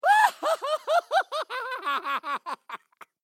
Звуки злодейские
Хохот безумного учёного